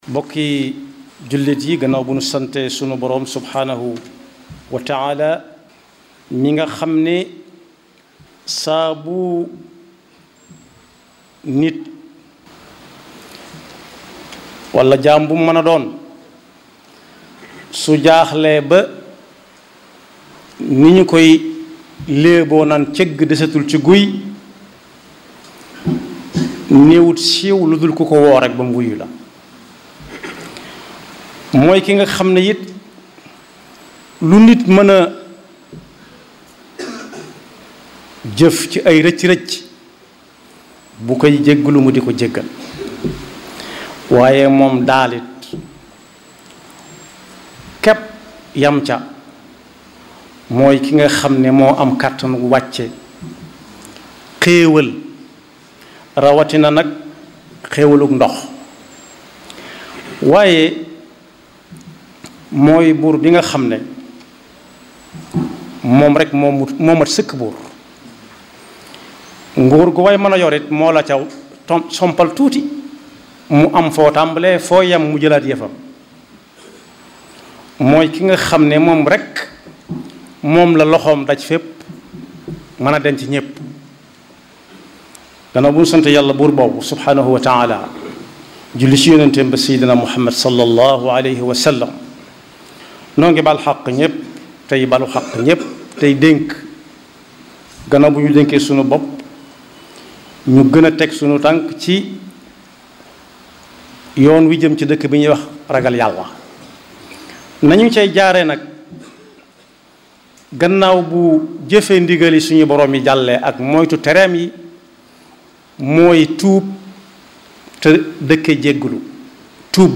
Écouter et Télécharger Les Khoutba